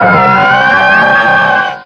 Cri de Milobellus dans Pokémon X et Y.